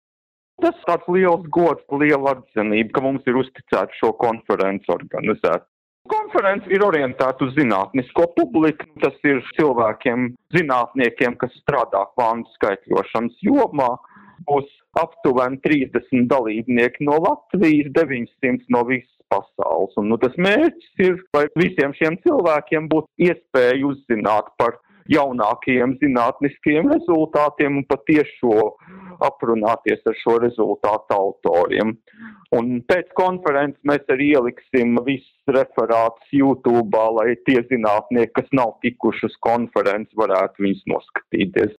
Saruna ar LU tenūrprofesoru datorzinātnēs, Latvijas Kvantu iniciatīvas vadītāju Andri Ambaini
Andris_Ambainis_par_kvantu_tehnologiju_zinatnisko_konferenci.mp3